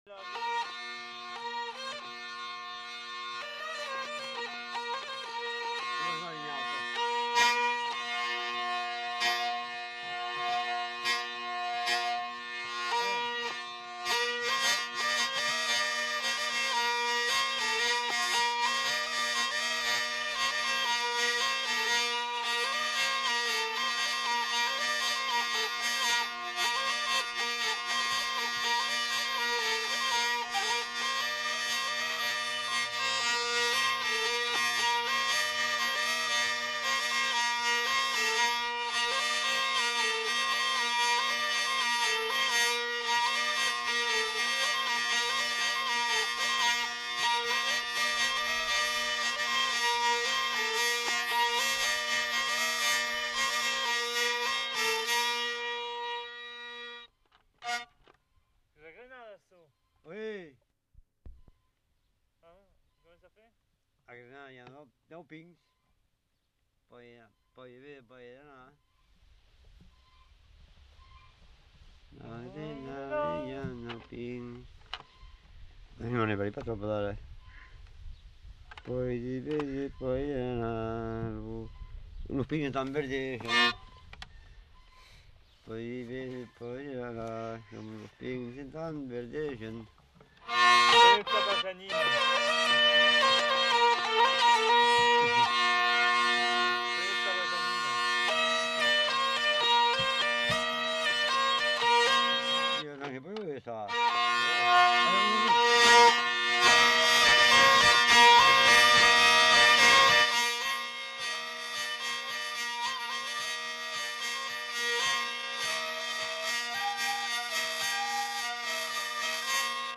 Aire culturelle : Gabardan
Genre : morceau instrumental
Instrument de musique : vielle à roue
Danse : rondeau